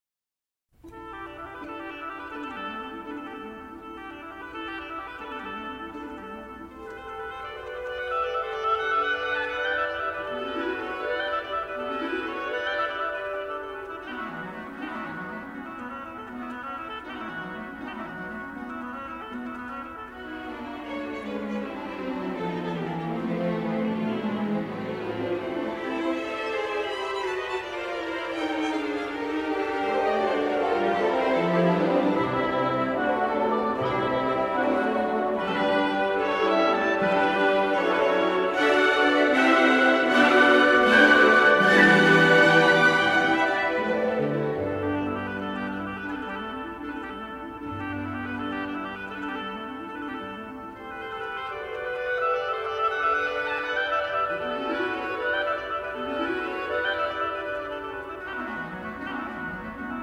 Oboe
Violin
Harpsichord
Cello
Viola da gamba
Chamber Orchestra